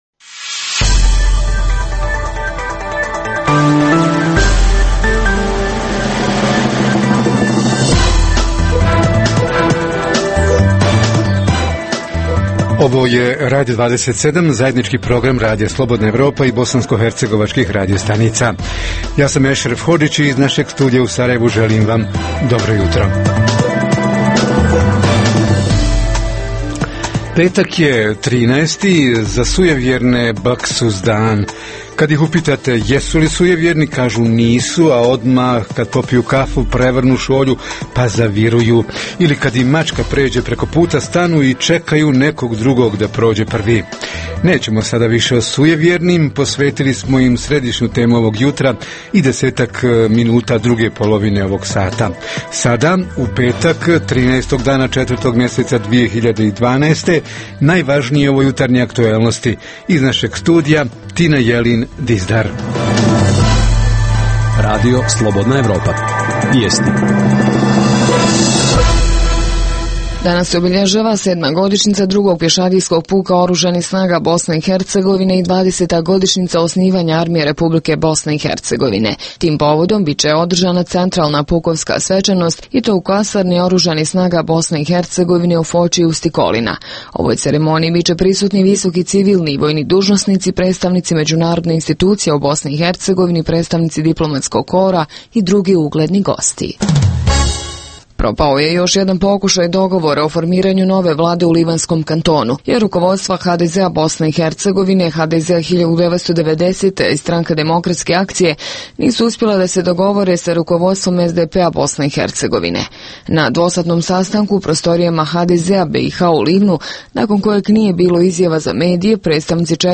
Petak, 13. – neki bi rado ostali u kući i prespavali ovaj dan, drugi će biti posebno oprezni – raste li broj sujevjernih s padom životnog standarda? Reporteri iz cijele BiH javljaju o najaktuelnijim događajima u njihovim sredinama.
Redovni sadržaji jutarnjeg programa za BiH su i vijesti i muzika.